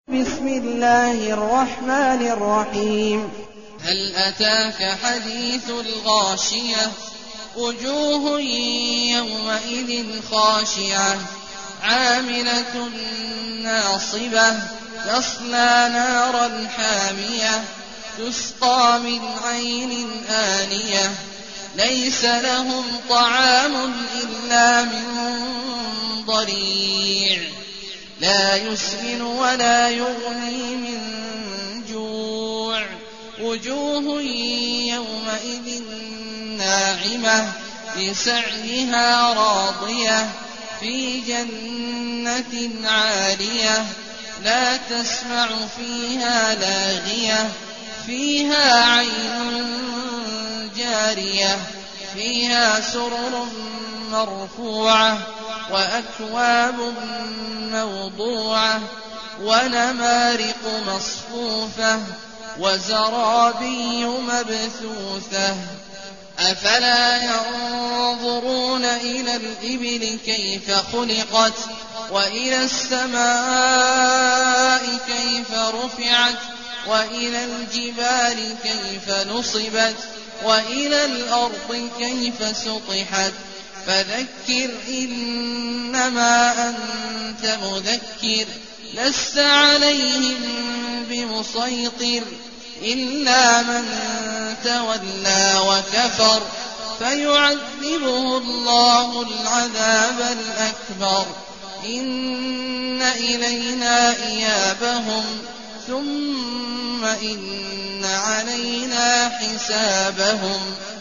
المكان: المسجد الحرام الشيخ: عبد الله عواد الجهني عبد الله عواد الجهني الغاشية